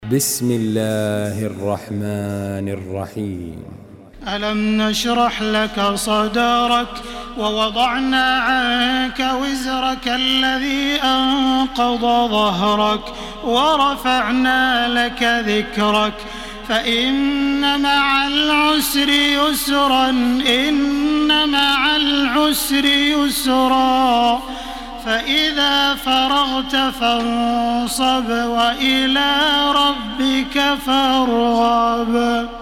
Surah আশ-শারহ MP3 by Makkah Taraweeh 1433 in Hafs An Asim narration.
Murattal Hafs An Asim